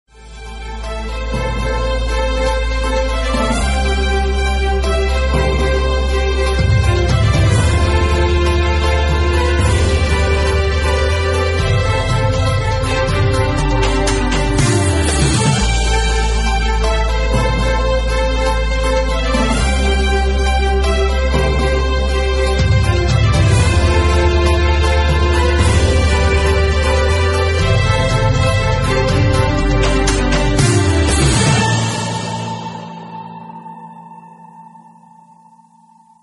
Sintonia de la cadena